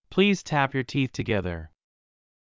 ﾌﾟﾘｰｽﾞ ﾀｯﾌﾟ ﾕｱ ﾃｨｰｽ ﾄｩｹﾞｻﾞｰ